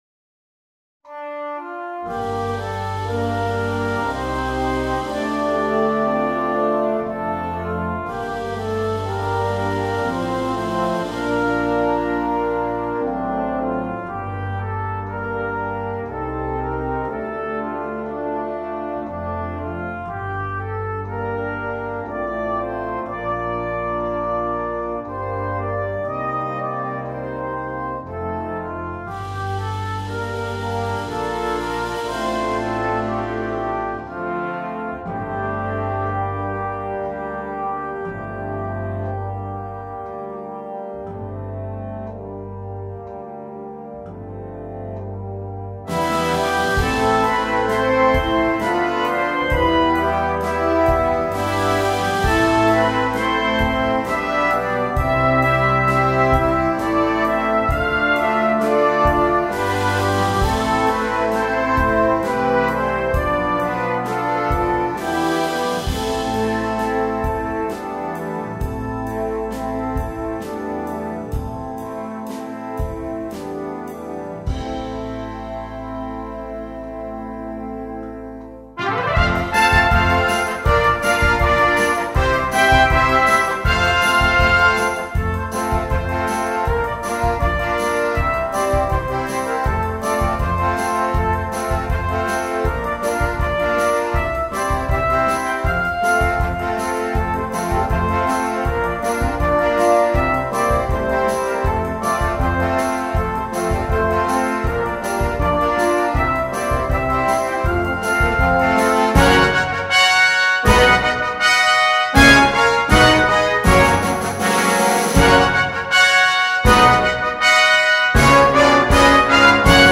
2. Brass Band
Full Band
without solo instrument
Entertainment